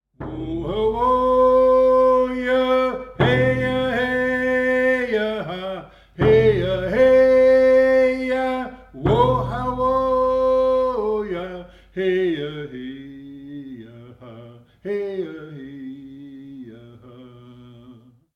Listen to a song by Roy Henry Vickers from the film